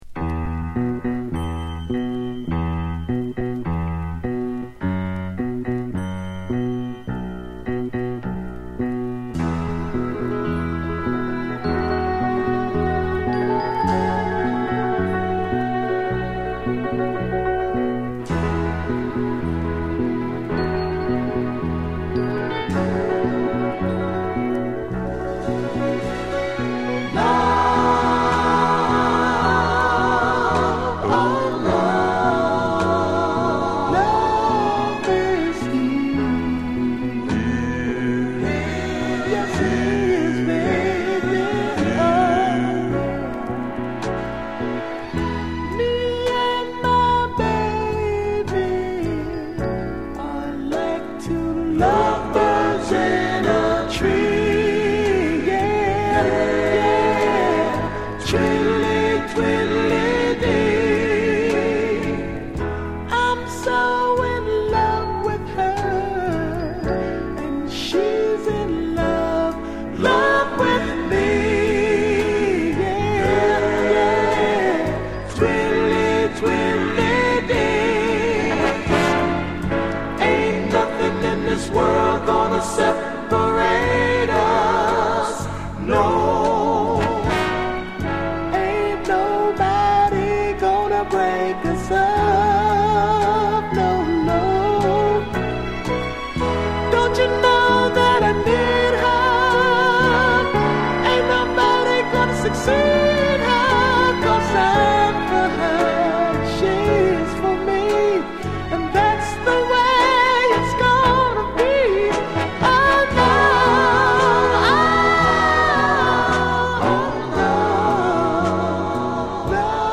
Genre: Northern Soul, Philly Style
Both sides are sublime Philly soul rarities... very tasty!